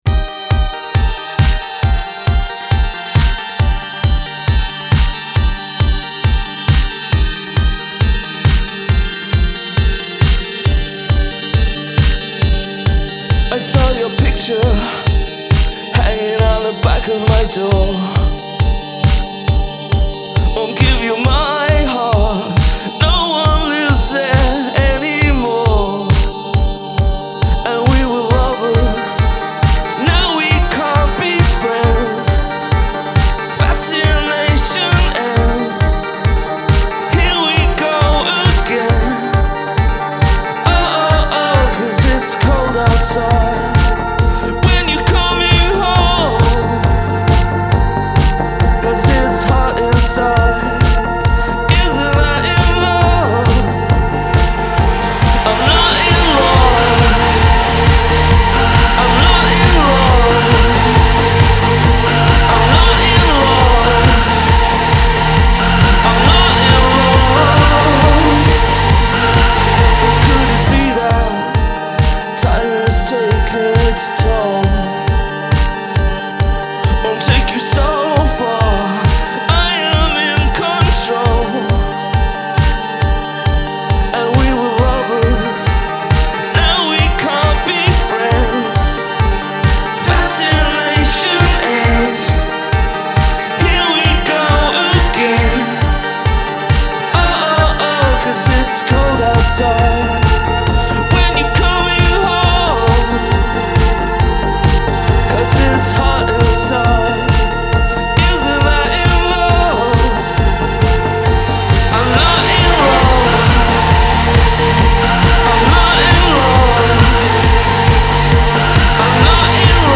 gothy electronic spasms
juggernaut synths